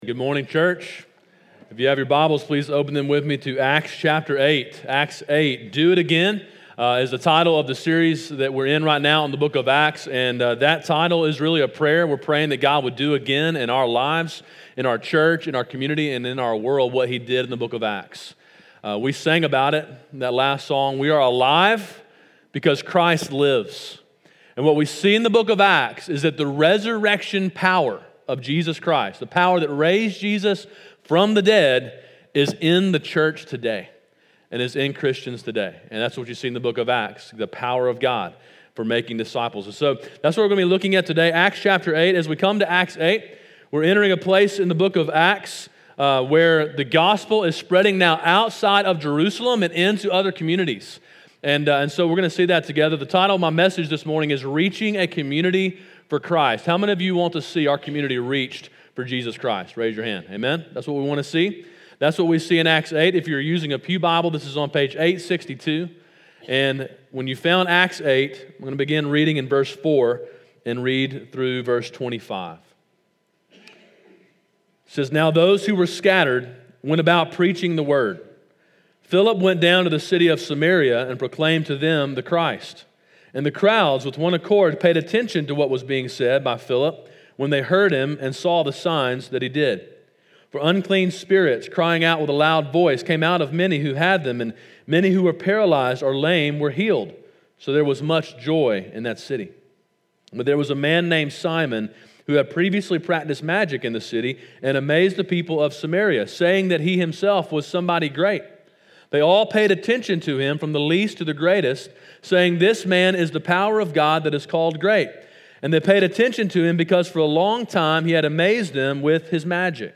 sermon6.23.19.mp3